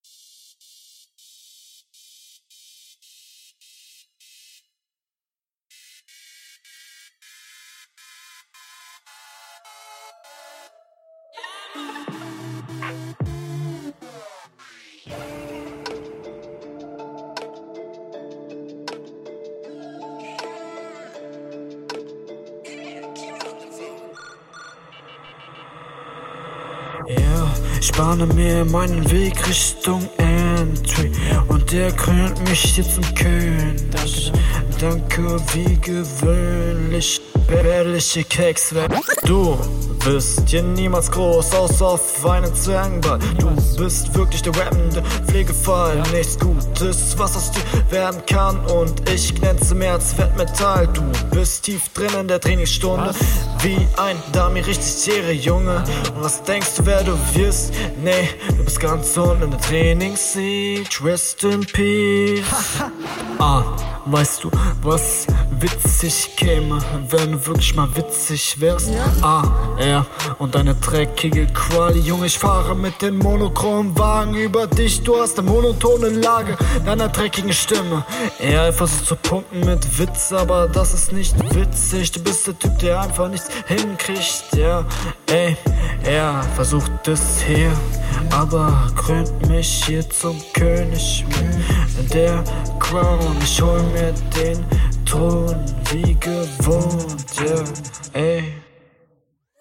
Es ist alles verständlich, macht die Hörqualität aber nicht viel besser.
Interessanter Beat schon mal.